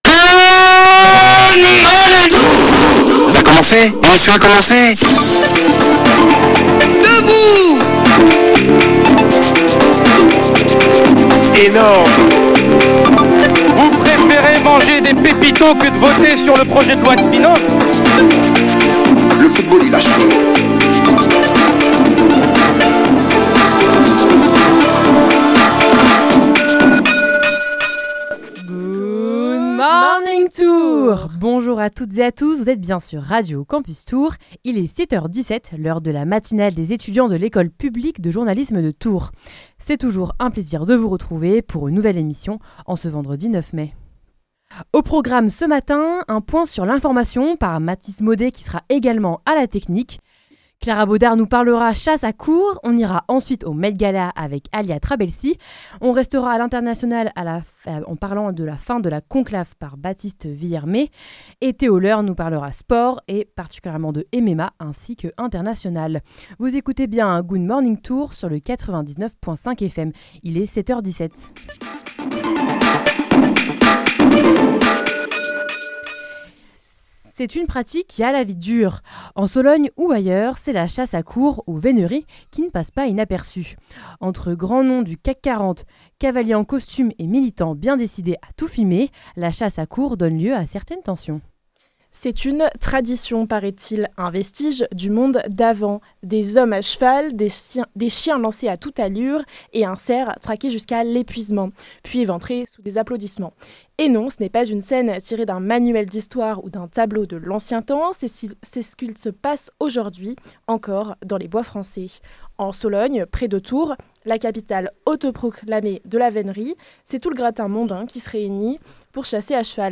La matinale des étudiants de l’école publique de journalisme de Tours, le vendredi de 7h15 à 8h15.